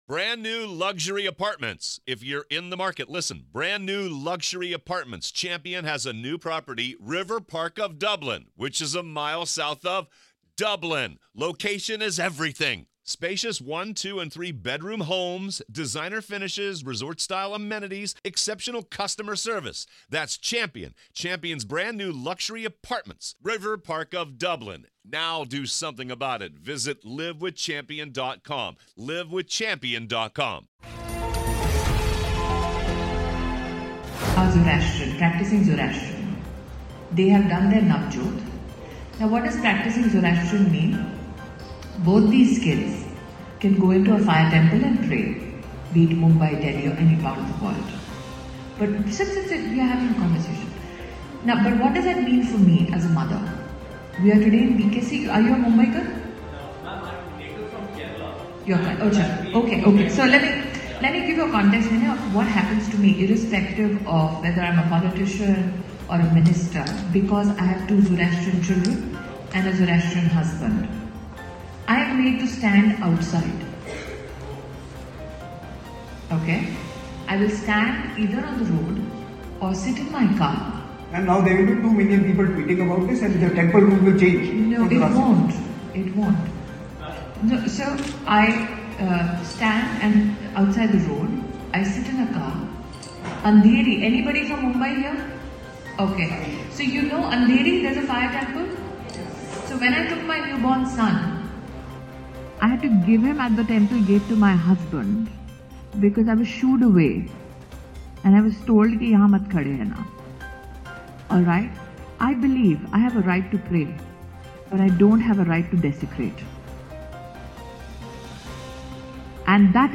न्यूज़ रिपोर्ट - News Report Hindi / सबरीमाला मंदिर विवाद पर बोली स्मृति ईरानी , सुप्रीम कोर्ट का खुलेआम नहीं कर सकती विरोध